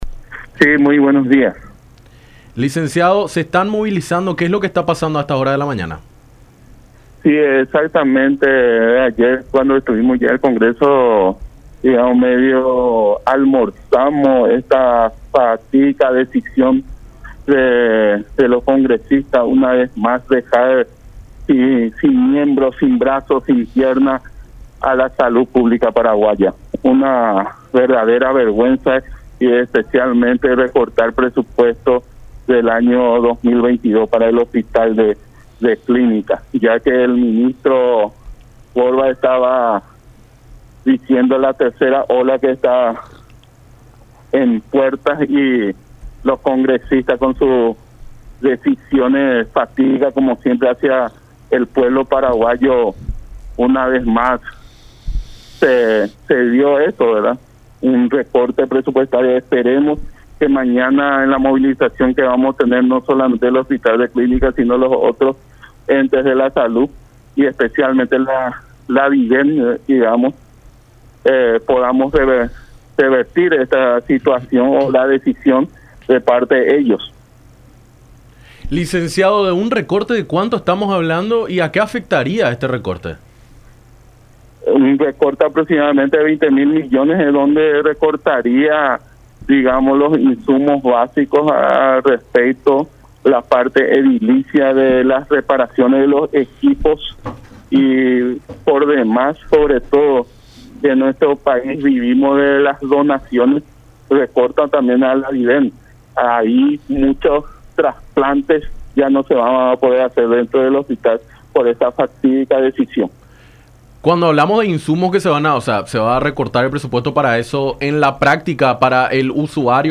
en charla con Enfoque 800 por La Unión.